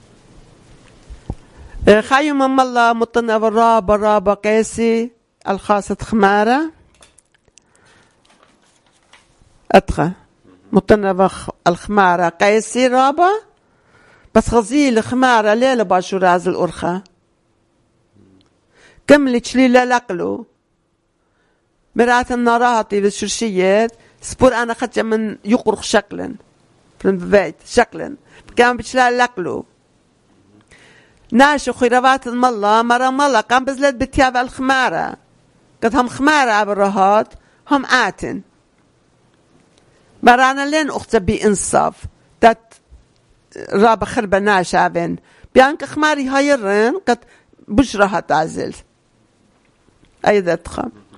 Urmi, Christian: Kindness to a Donkey